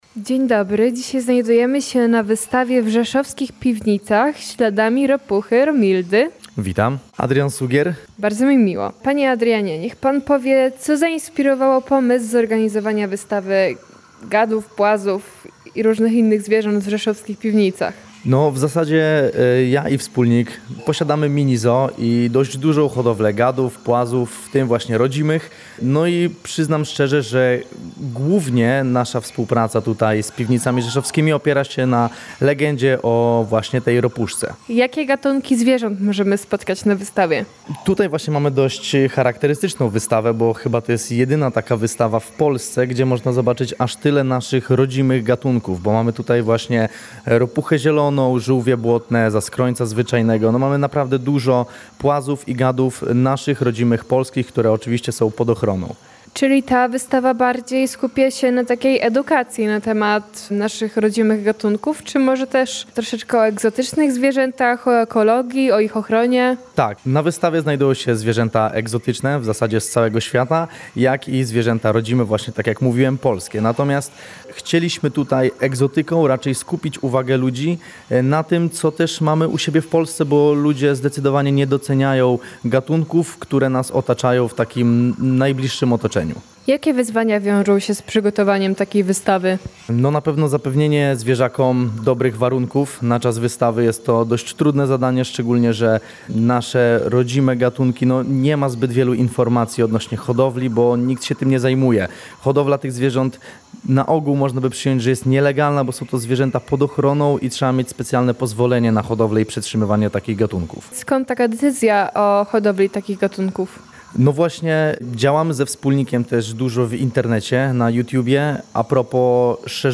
Audycje • W Rzeszowskich Piwnicach otwarto wyjątkową wystawę "Śladami Ropuchy Romildy", która nawiązuje do lokalnej legendy o rechocie żab z dawnego